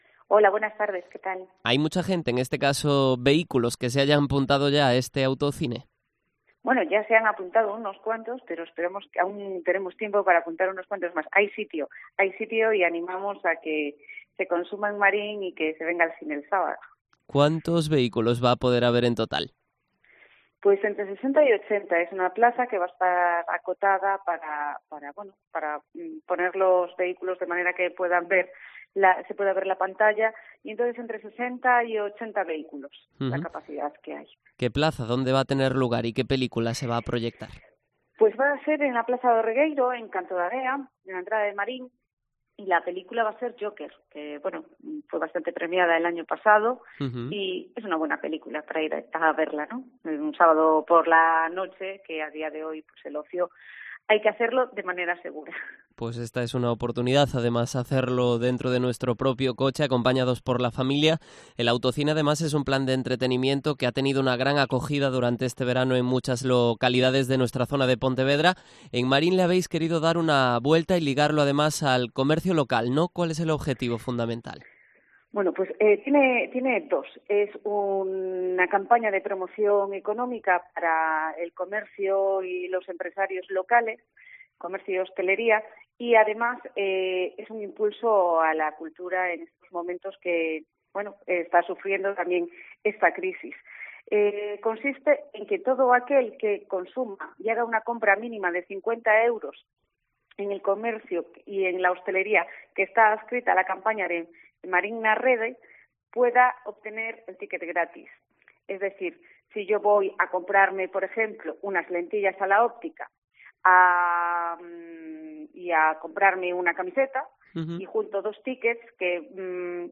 Entrevista a Beatriz Rodríguez, edil de Cultura de Marín